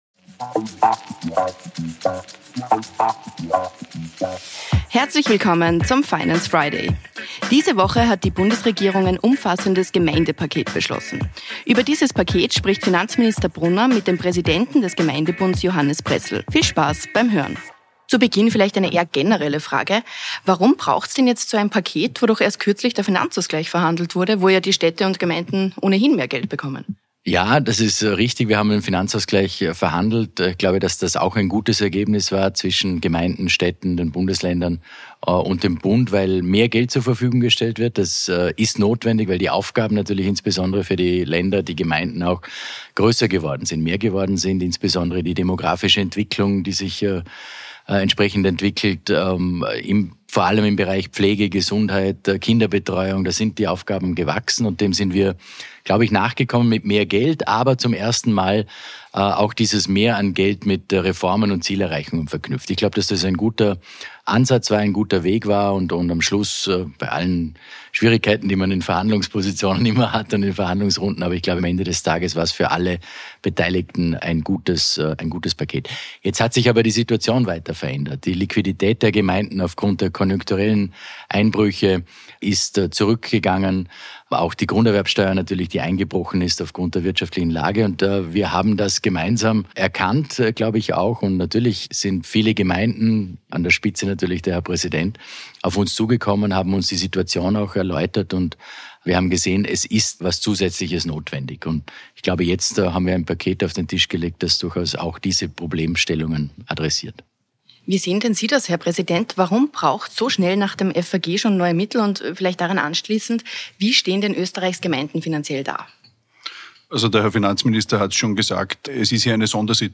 Folge 92: Im Gespräch mit Johannes Pressl ~ Finance Friday Podcast
Beschreibung vor 1 Jahr Diese Woche hat die Bundesregierung ein umfassendes Gemeinde-Paket beschlossen – warum es so ein Paket braucht, was es beinhaltet und ob es sich beim Gemeinde-Paket um ein Wahlzuckerl handelt, besprechen Finanzminister Magnus Brunner und der Präsident des österreichischen Gemeindebunds, Johannes Pressl, in der aktuellen Folge des „Finance Friday“.